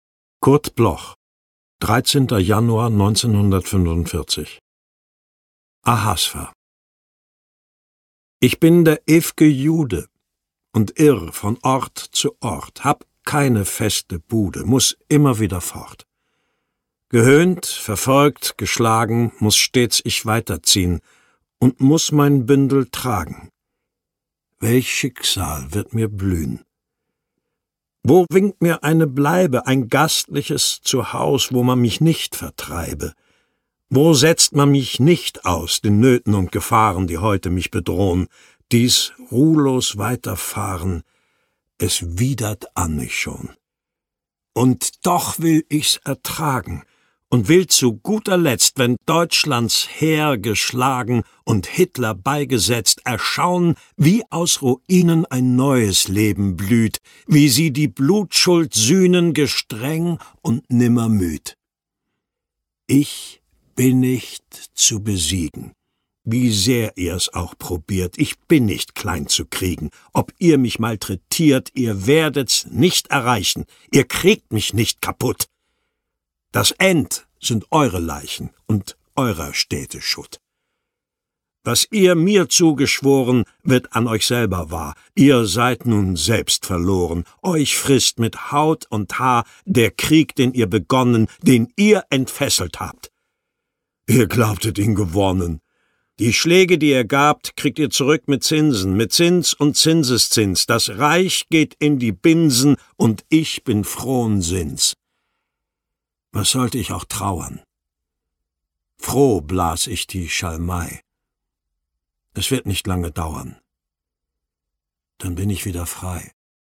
Ahasverus voorgedragen door Rainer Bock